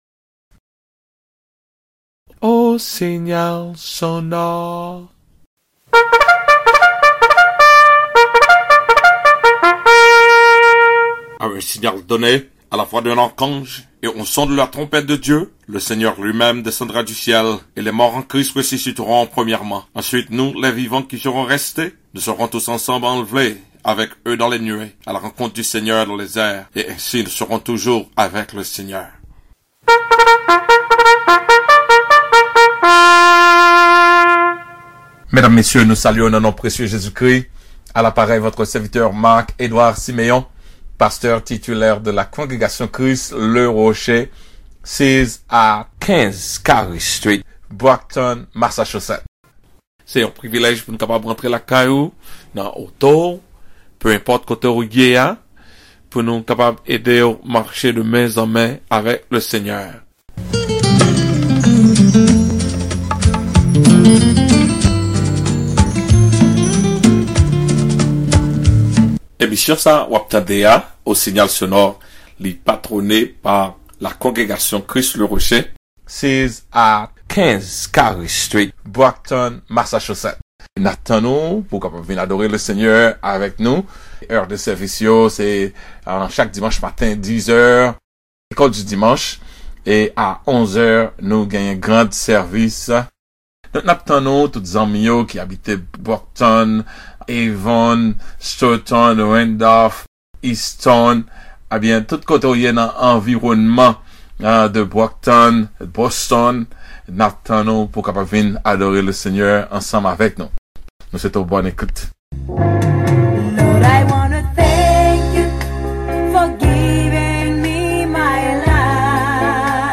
CHRIST THE ROCK CONGREGATION SUNDAY AUGUST 2ND 2020